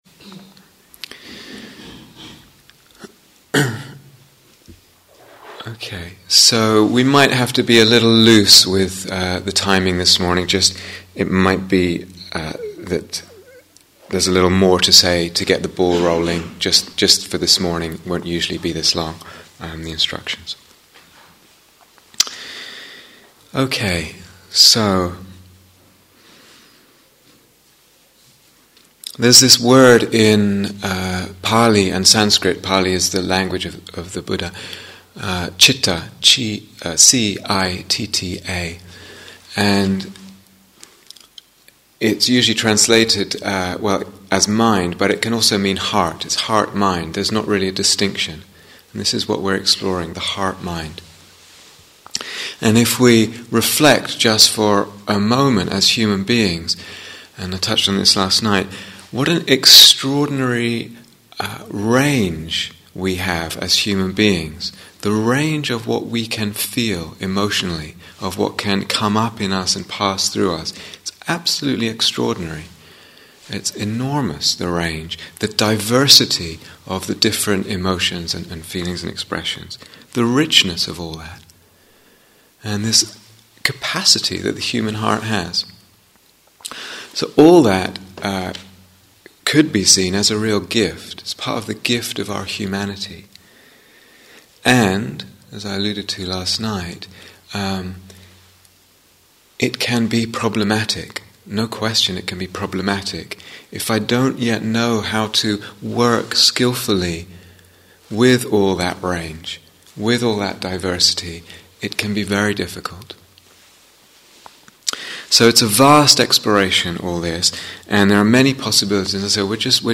Working with the Emotional Body (Instructions and Guided Meditation: Day One)